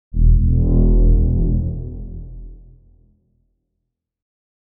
Perfect for bass, cinematic, dark.
bass cinematic dark deep drop electronic evil future sound effect free sound royalty free Movies & TV